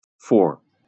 amer. enPR: fôr, IPA/fɔr/ lub /foʊr/, SAMPA/fOr\/
wymowa amerykańska?/i